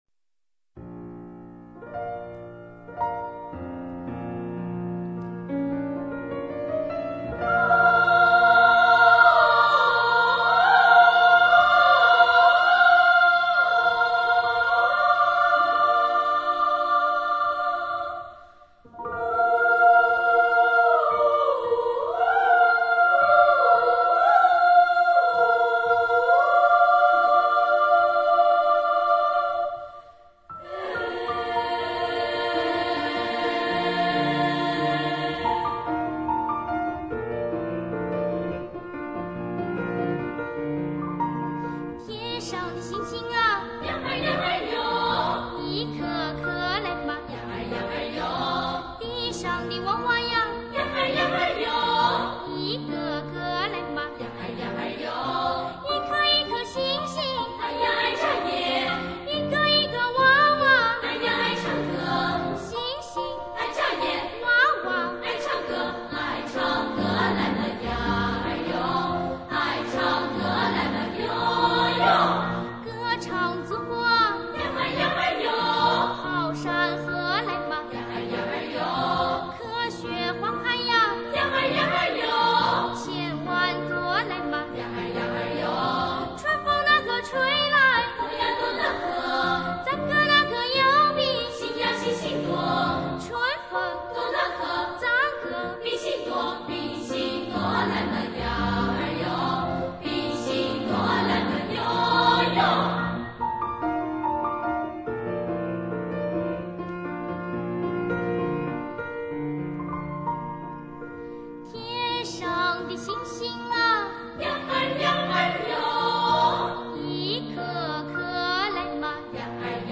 钢琴伴奏